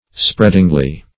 Search Result for " spreadingly" : The Collaborative International Dictionary of English v.0.48: Spreadingly \Spread"ing*ly\, adv.